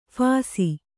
♪ phāsi